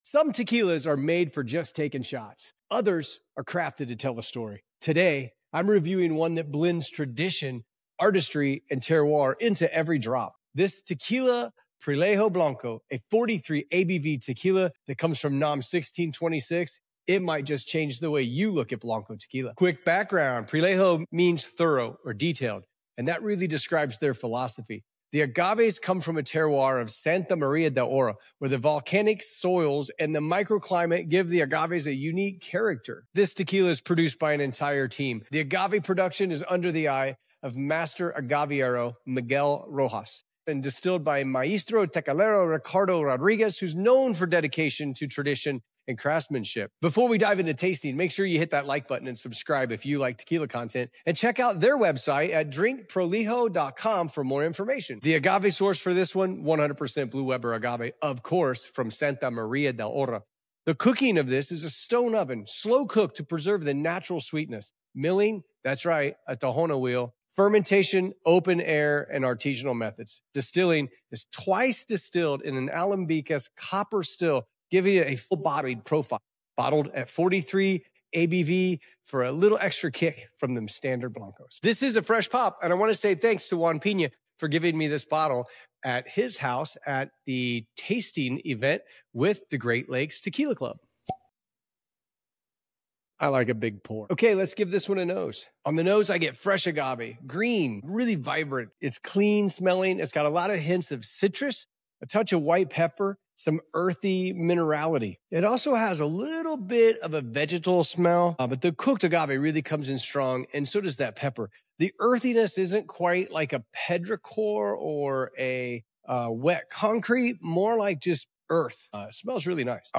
In this long-form tequila review, I take a deep dive into Prolijo Tequila Blanco (NOM 1626), an artisanal tequila cooked in a stone oven, tahona crushed, and twice distilled in copper stills.